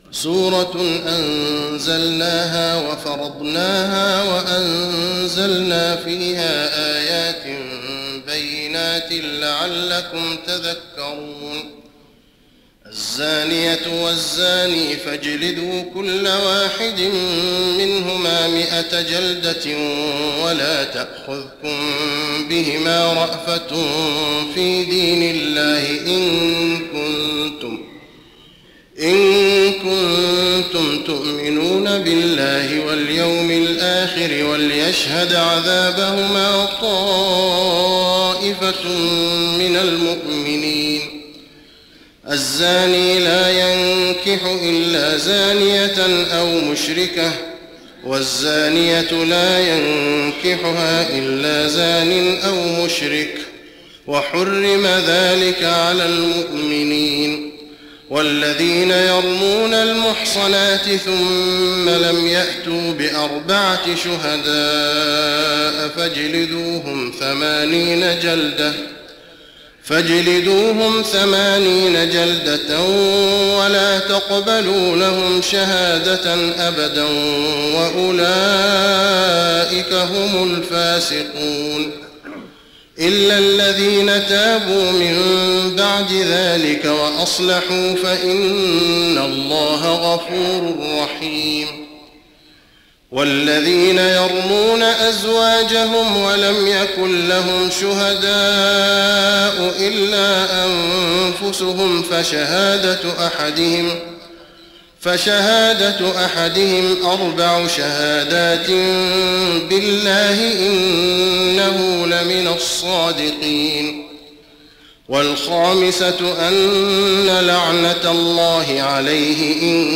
تراويح رمضان 1415هـ سورة النور كاملة Taraweeh Ramadan 1415H from Surah An-Noor > تراويح الحرم النبوي عام 1415 🕌 > التراويح - تلاوات الحرمين